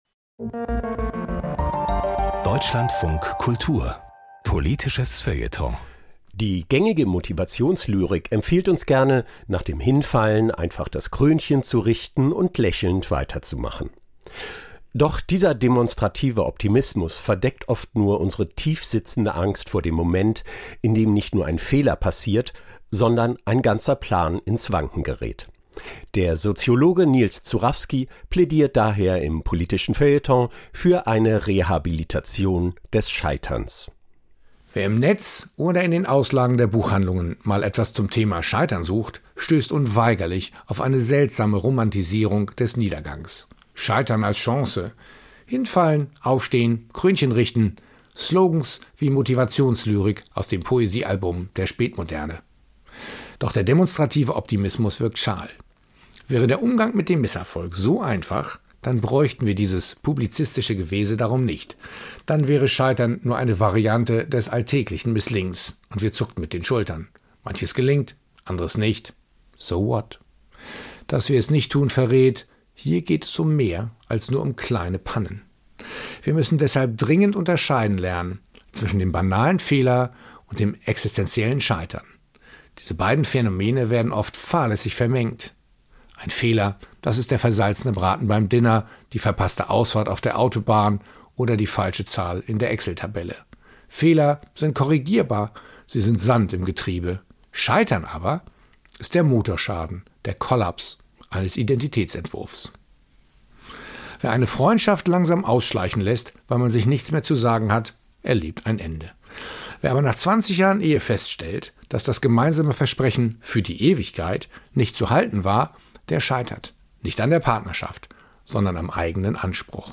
Hinfallen, aufstehen, Krönchen richten? Von wegen! (Kommentar zum Scheitern)